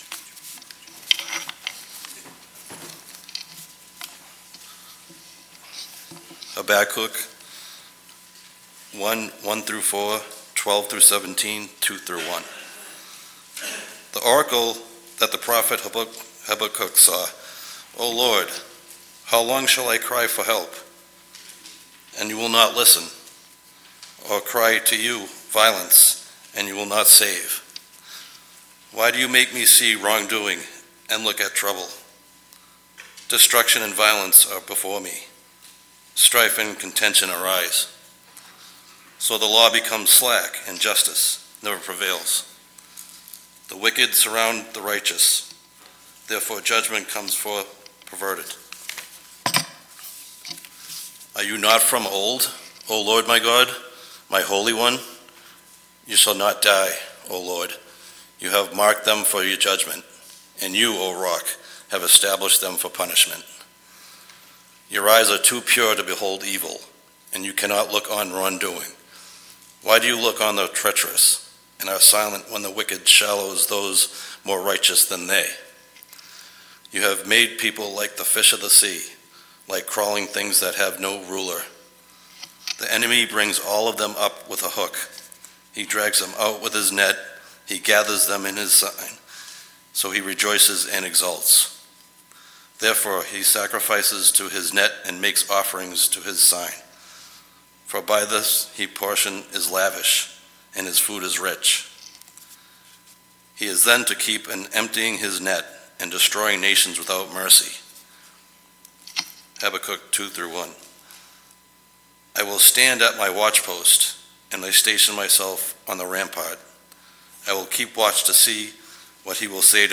Scripture-Reading-and-Sermon-June-9-2024.mp3